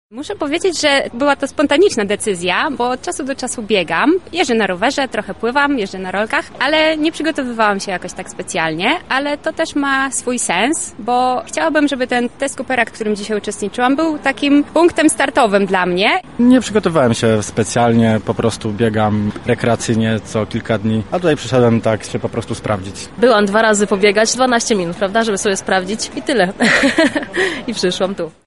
Sonda-bieg-coopera.mp3